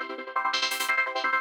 SaS_MovingPad04_170-C.wav